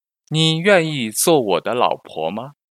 Nǐ yuànyì zuò wǒ de lǎopó ma?
ニー ユェンイー ズゥォ ウォ デァ ラオポォ マー？